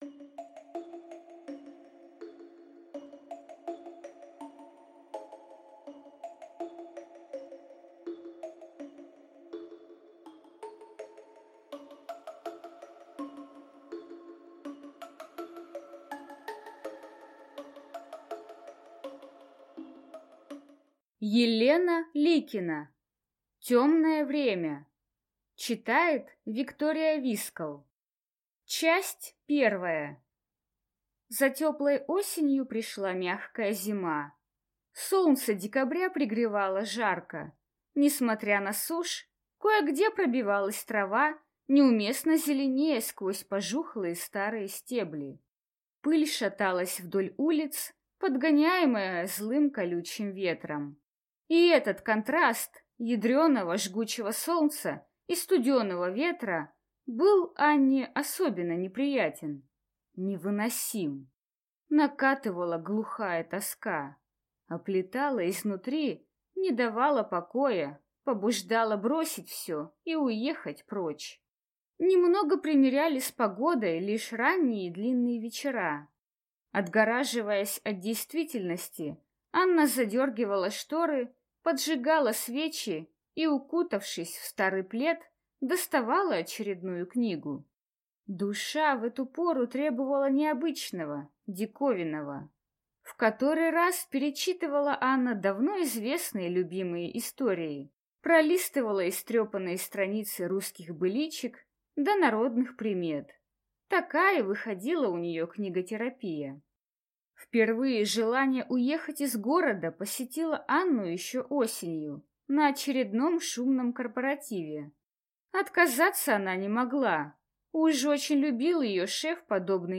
Аудиокнига Тёмное время | Библиотека аудиокниг